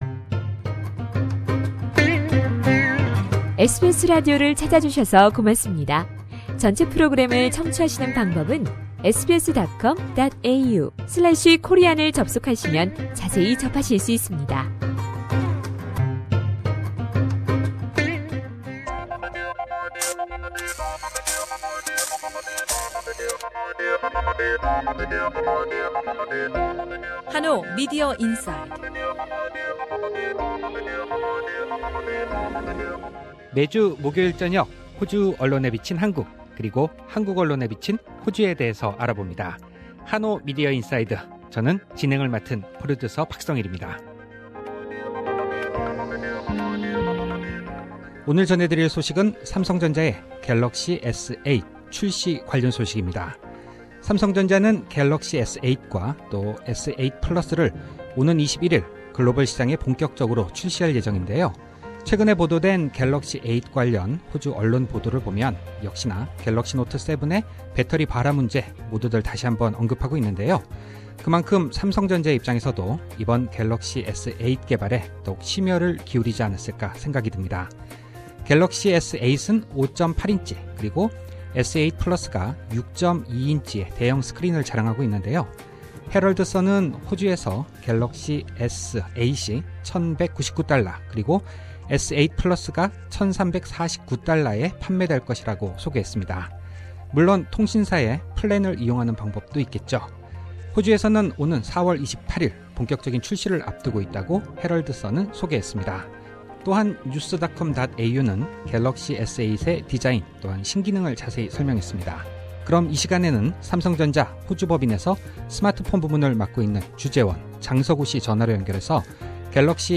전화로 연결해 28일 호주 출시 예정인 갤럭시S8에 대해 이야기 나눠본다.